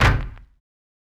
Kick (8).wav